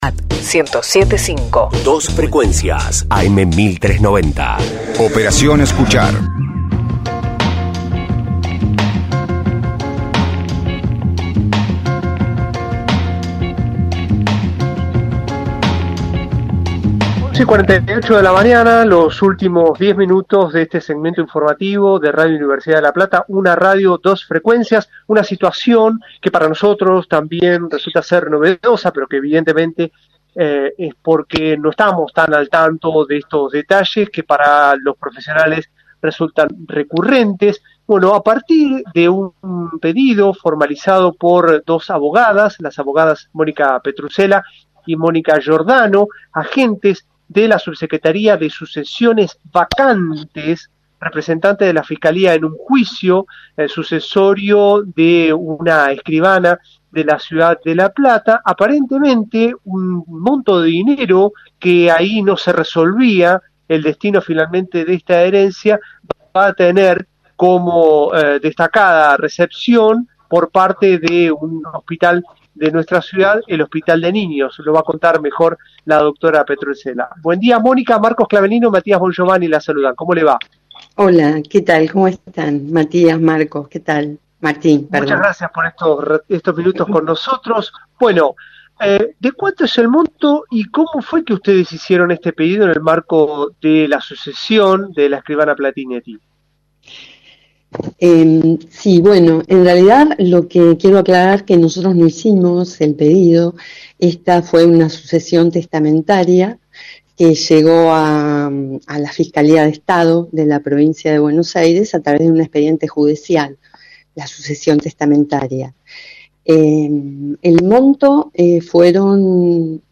Una herencia para el Hospital de Niños: entrevista